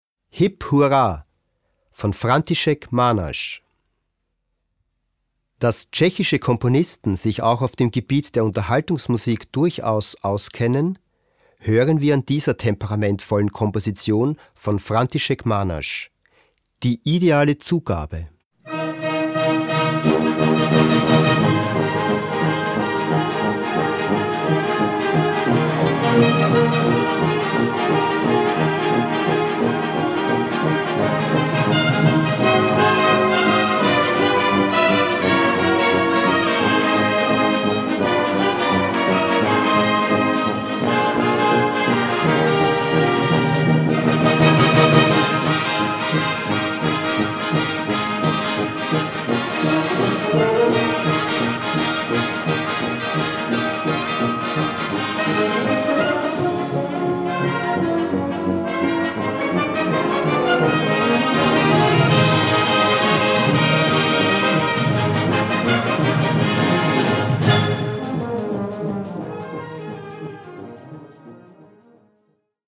Besetzung: Blasorchester
temperamentvollen Komposition